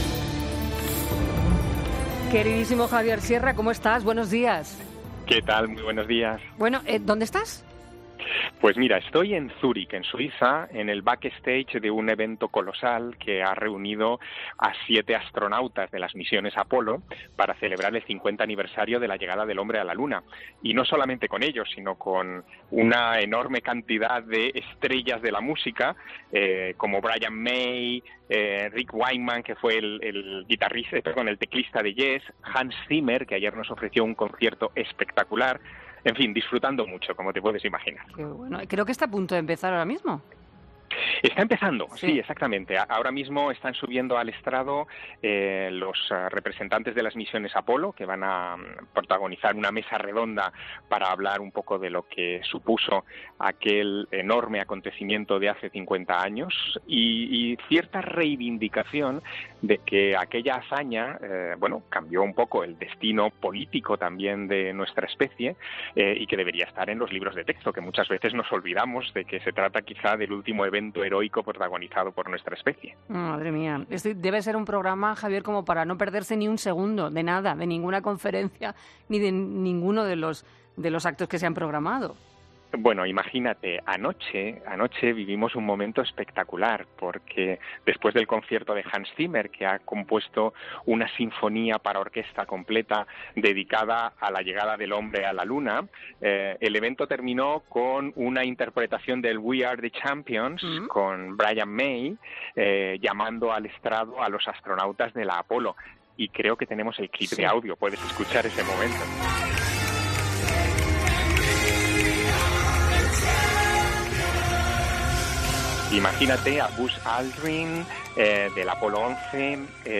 Hablamos con Javier Sierra, que se encuentra en el Starmus, el Festival Internacional dedicado a la astronomía